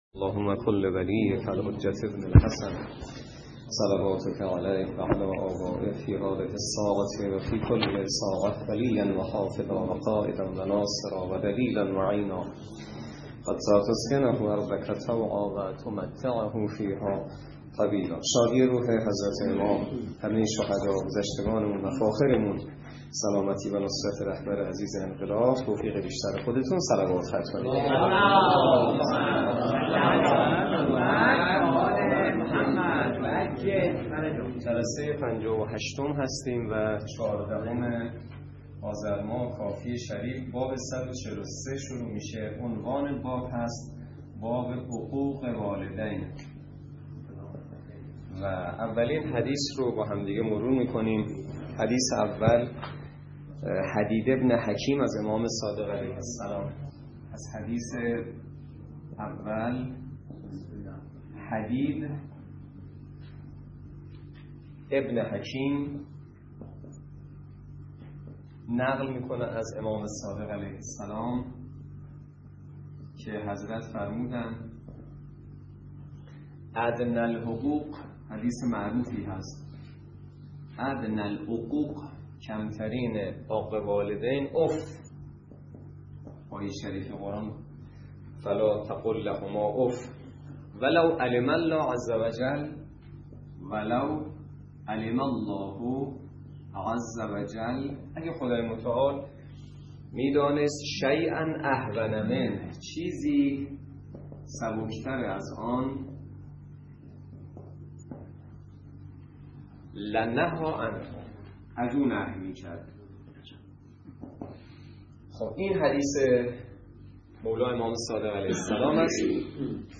درس فقه حجت الاسلام والمسلمین سید سعید حسینی نماینده مقام معظم رهبری در منطقه و امام جمعه کاشان موضوع: فقه اجاره - جلسه ۵۸